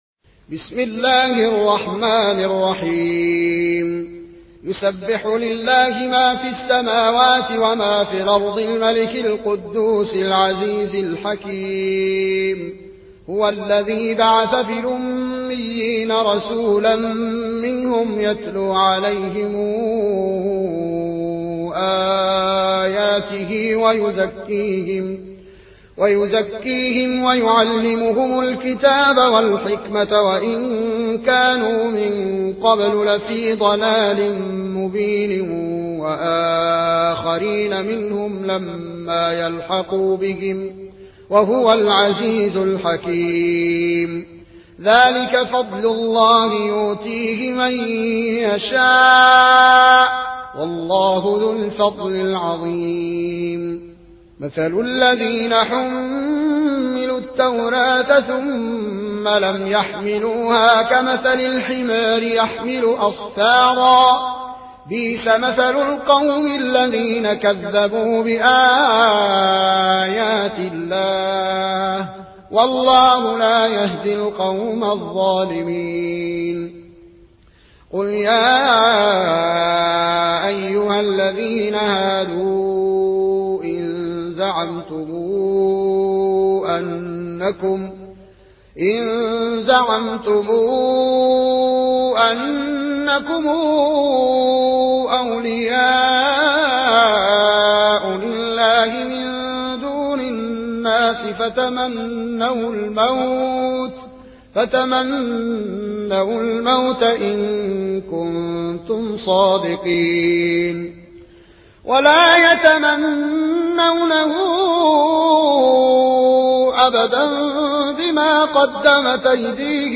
Riwayat Warsh